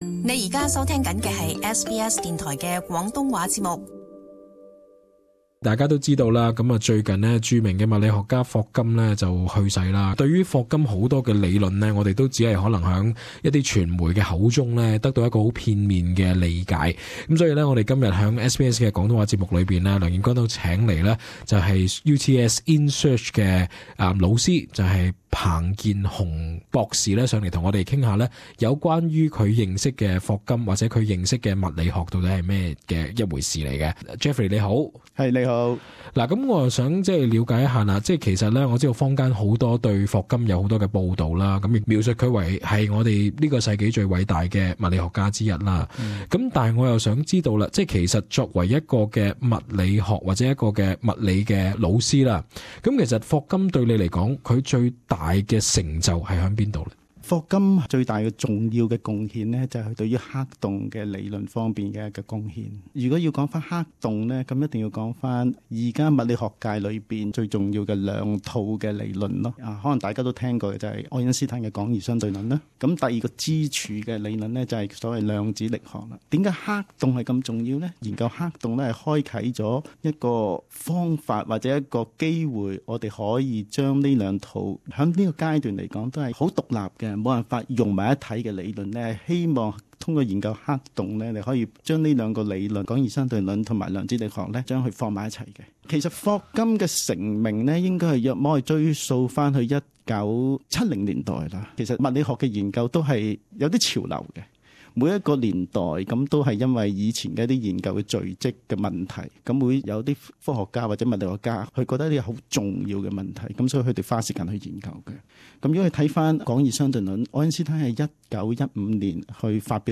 【特別專訪】淺談霍金的理論與成就